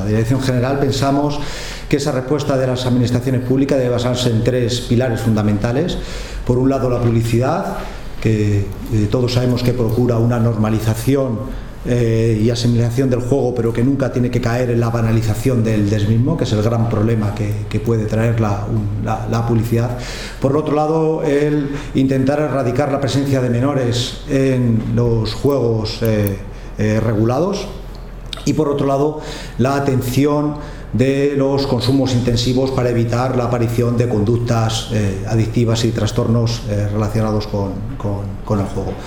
Una jornada de HISPACOOP sobre juego responsable en la sede de la ONCE repasa los retos que afronta este compromiso y acoge la presentación del último proyecto de investigación sobre esta materia galardonado por la Organización